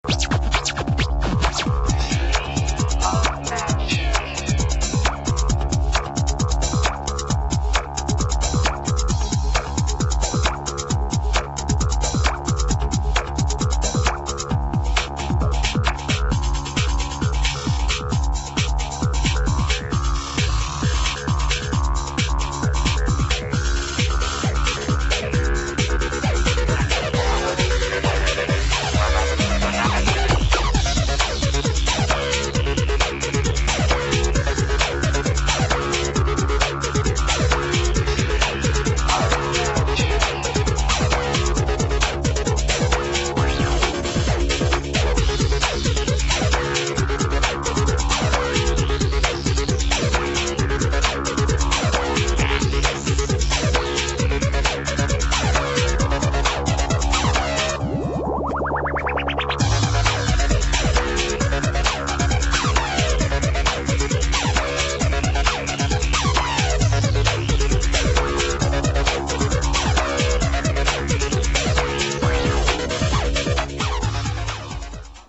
[ BREAKS | HOUSE ]